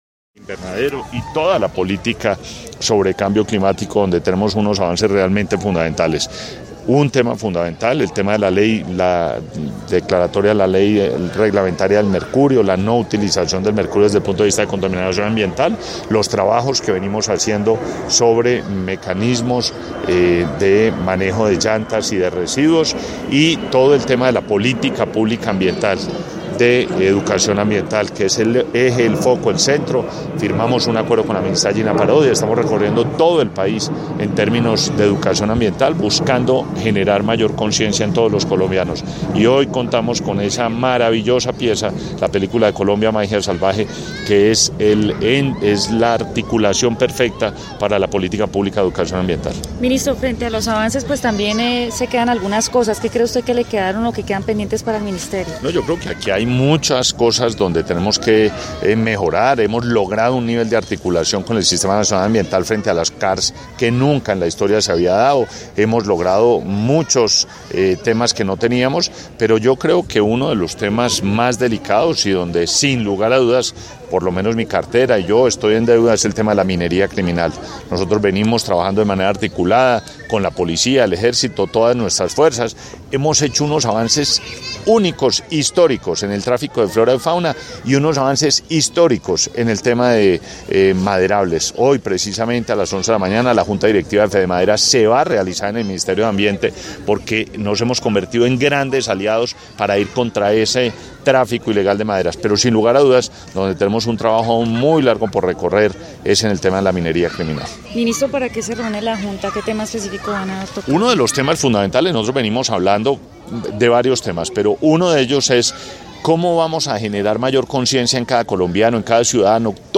Declaraciones del Ministro de Ambiente y Desarrollo Sostenible, Gabriel Vallejo López